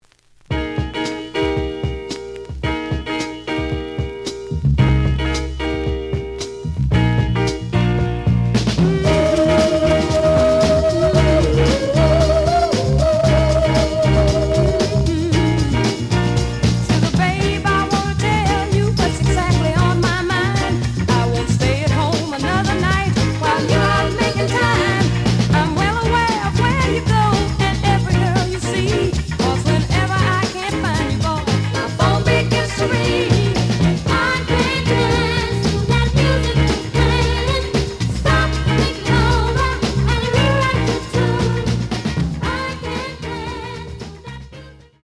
The audio sample is recorded from the actual item.
●Genre: Soul, 60's Soul
Slight noise on A side.
B side plays good.)